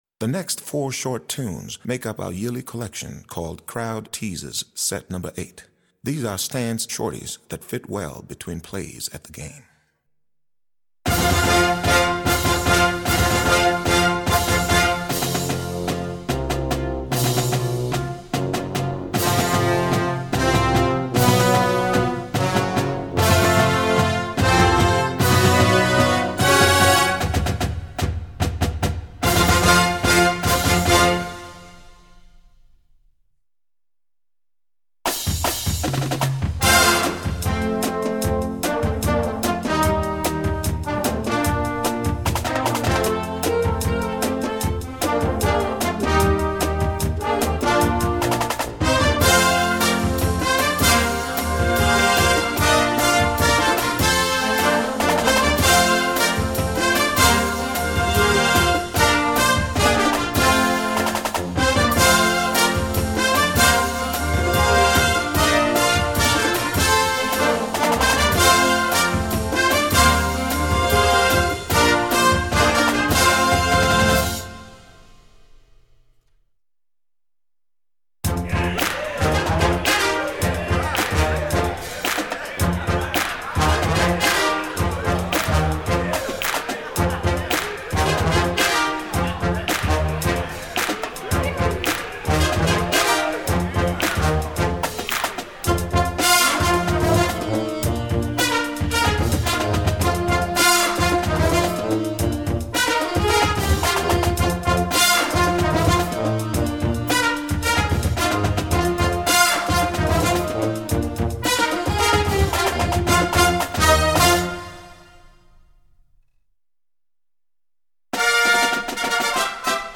Voicing: Marching Band